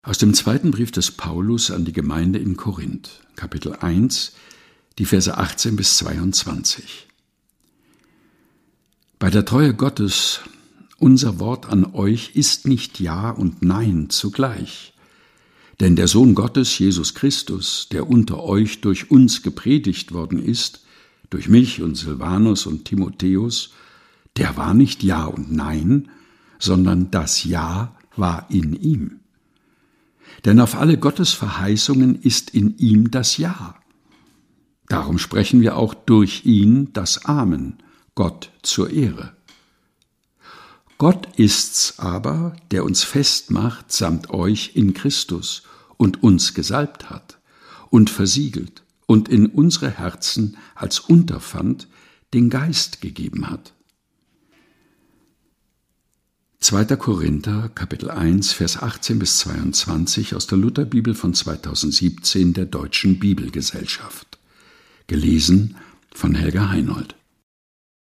liest: In Christus ist nur Ja ohne Nein (2. Korinther